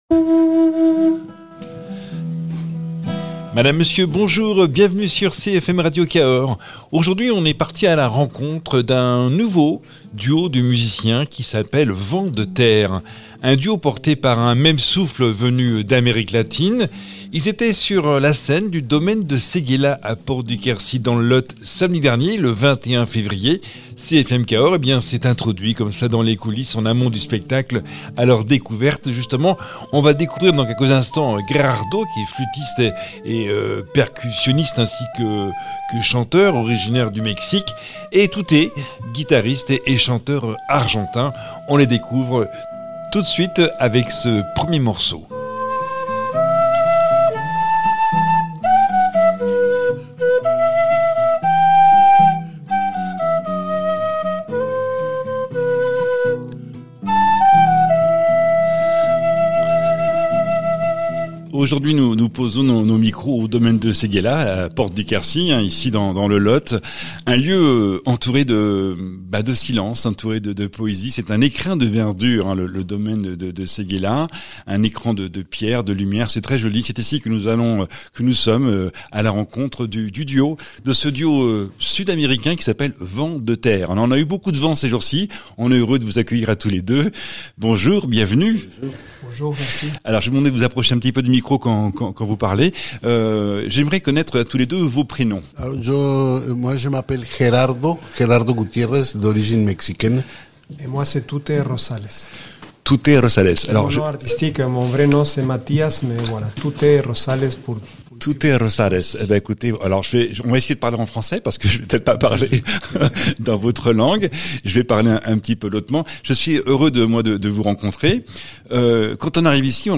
au cœur des musiques d’Amérique latine
flûtiste, percussionniste et chanteur originaire du Mexique
guitariste et chanteur argentin